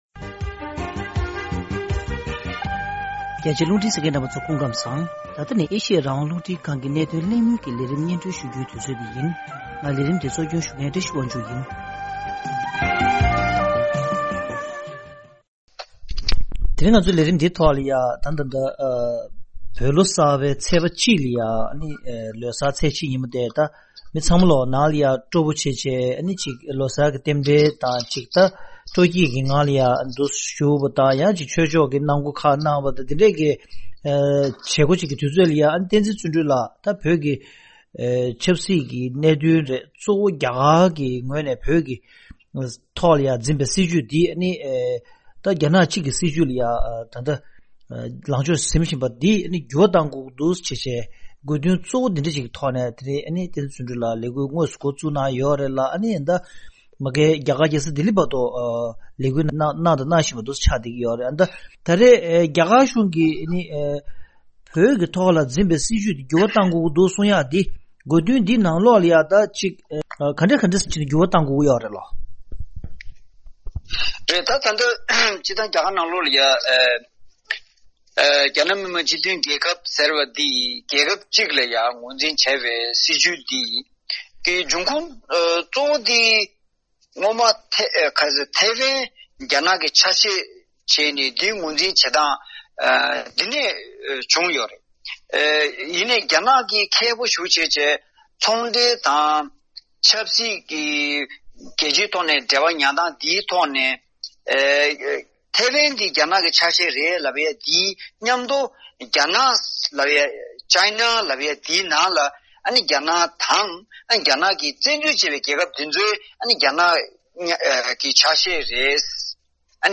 གནད་དོན་གླེང་མོལ་གྱི་ལས་རིམ